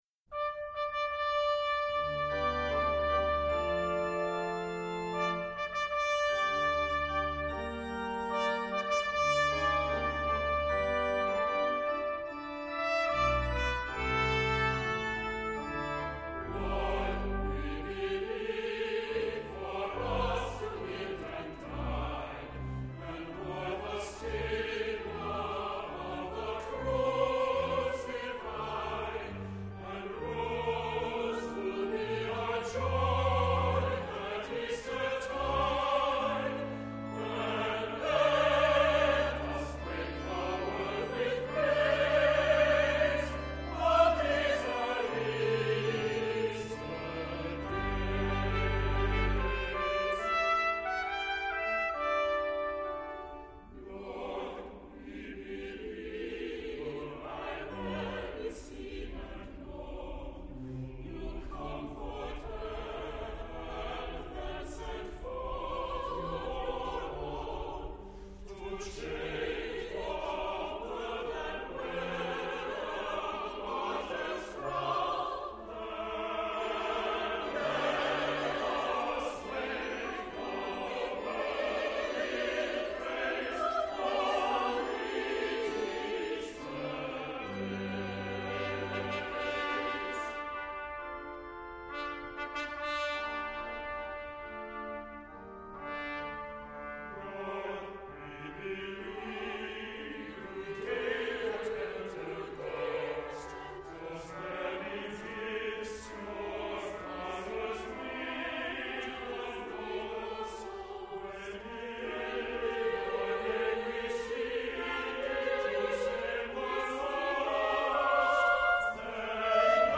Accompaniment:      Organ, Trumpet in B-flat;Trumpet in C
Music Category:      Choral
This triumphant setting